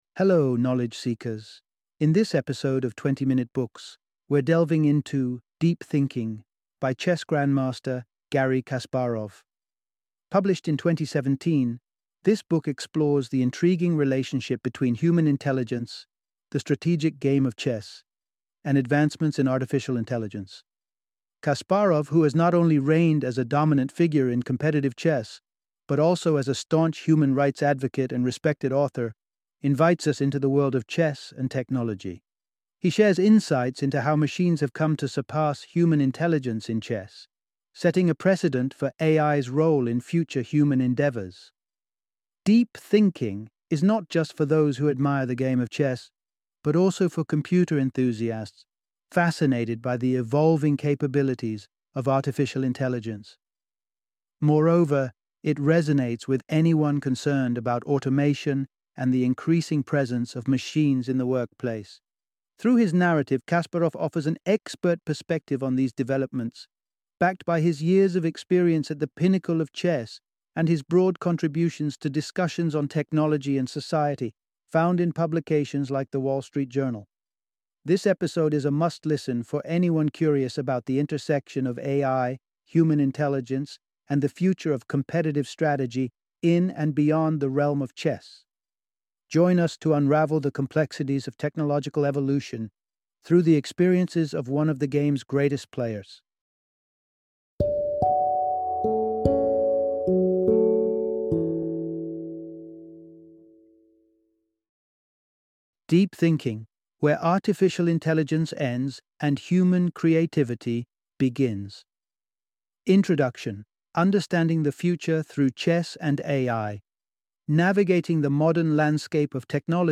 Deep Thinking - Audiobook Summary